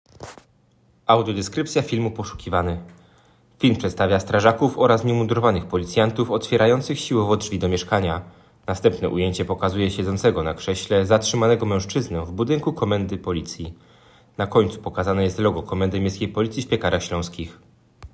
Opis nagrania: Audiodeskrypcja filmu poszukiwany